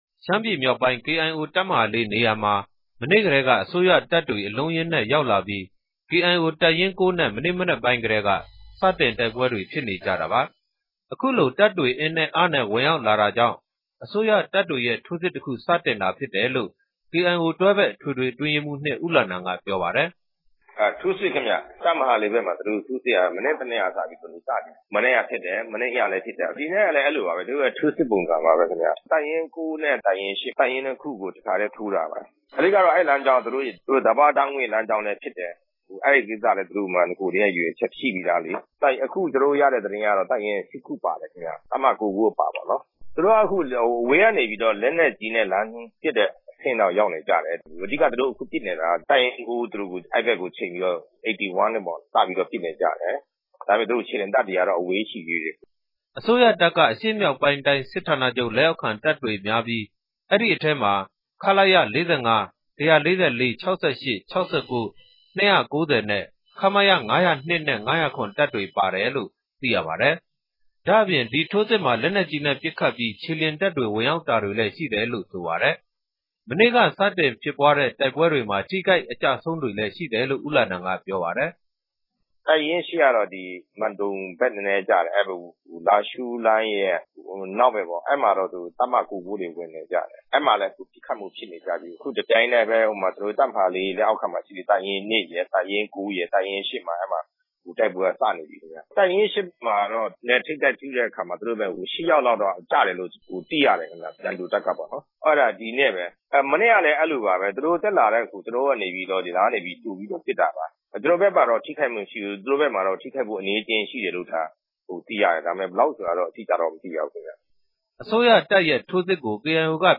စုစည်းတင်ပြချက်။
ဆက်သွယ်မေးမြန်းချက်။